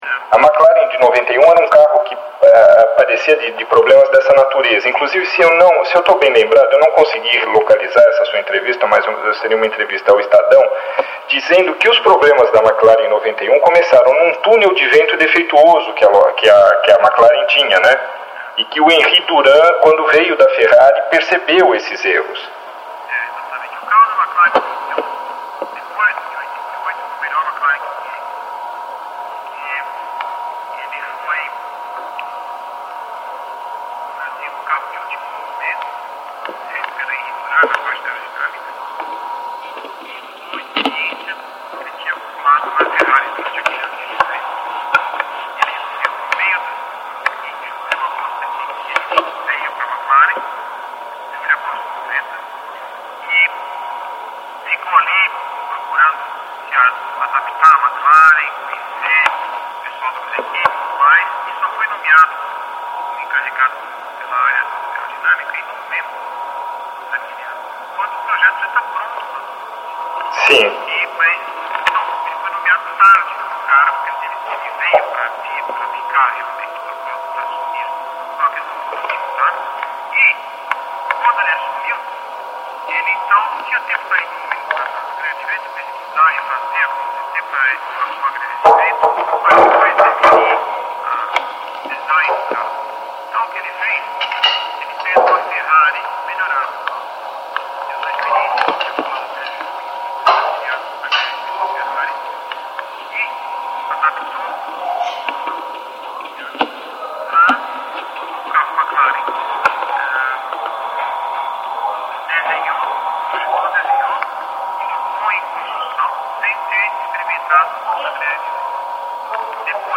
Senna – A entrevista 4 – GPTotal